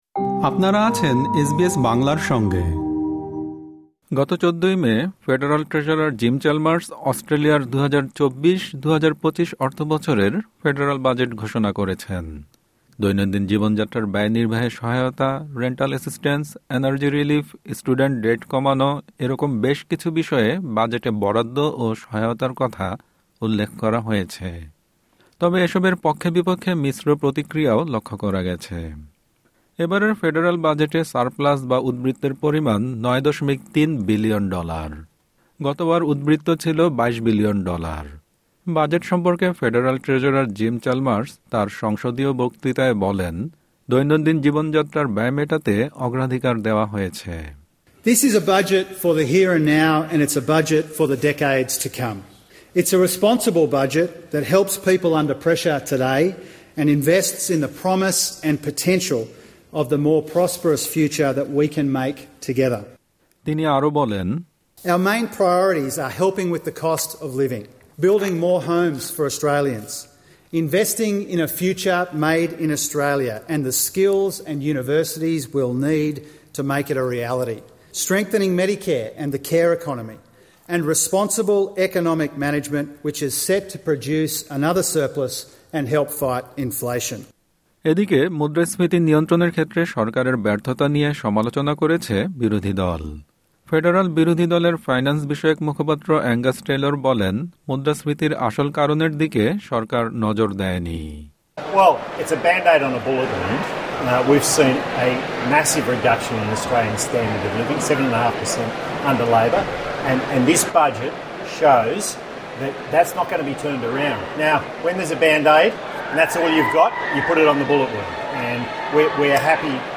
সাক্ষাৎকারটির দ্বিতীয় ও শেষ পর্বে রয়েছে ট্যাক্স কাট, স্টুডেন্ট লোন রিফর্ম এবং সারপ্লাস বাজেট নিয়ে আলোচনা।